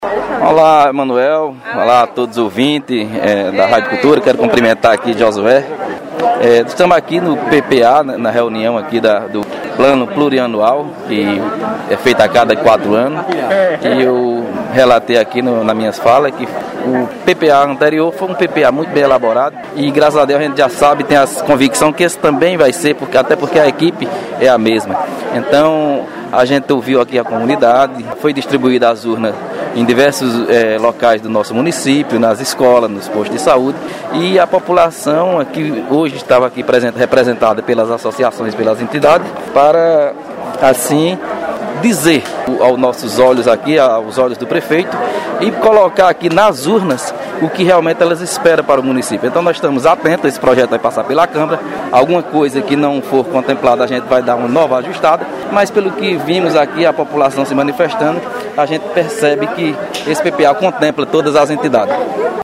O evento ocorreu no Salão Paroquial da Matriz de São Sebastião no centro de Ouricuri.
O Presidente a Câmara, Iran Severo, falou a respeito da abertura das discussões do PPA e sobre sua reivindicação, a qual ao lado dos demais vereadores presentes depositou na urna.